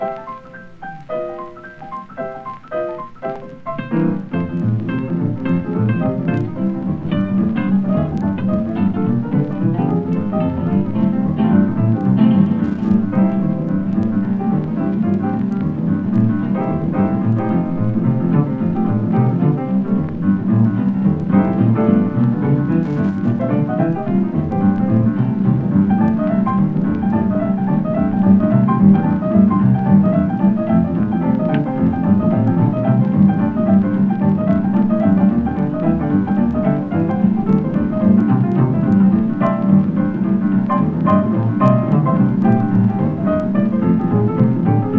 Jazz, Blues　USA　12inchレコード　33rpm　Mono